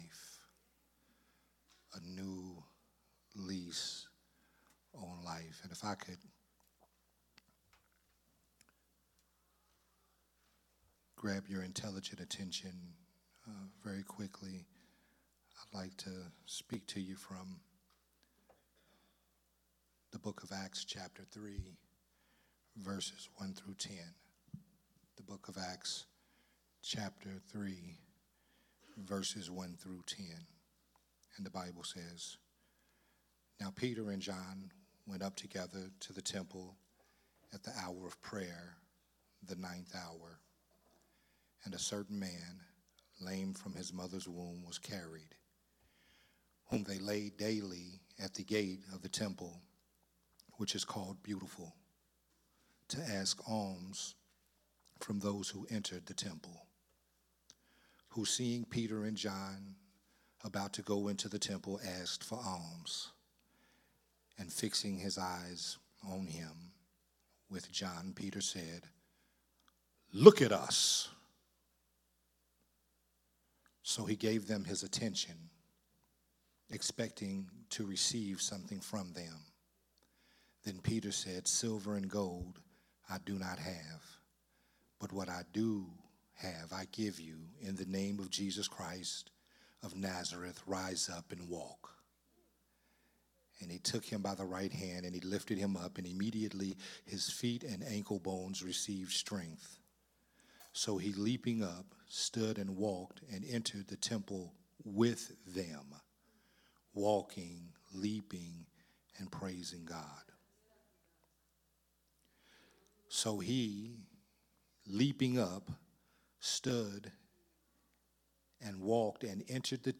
a Sunday Morning Worship Service sermon